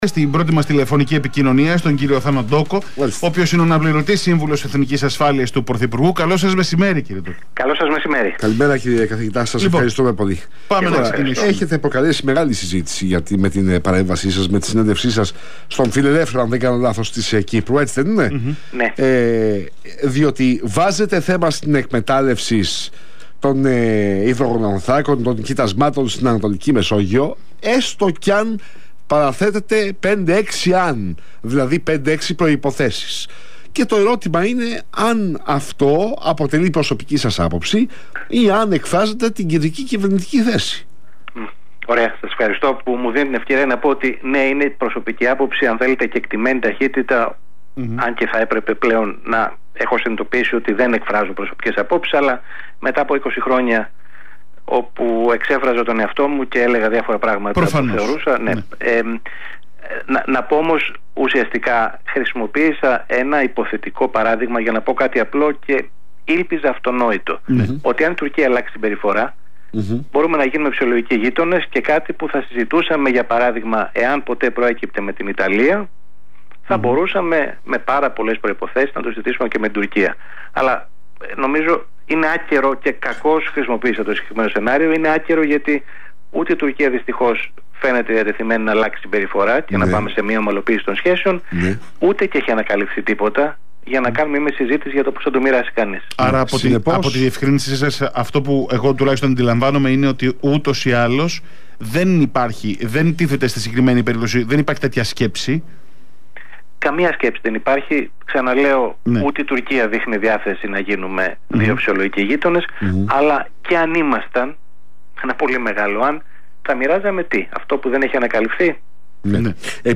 [ iEpikaira: Ακούστε ΕΔΩ ολόκληρη την συνέντευξη.